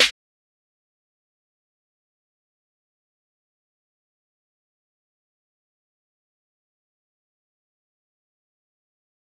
Snares
JJSnares (31).wav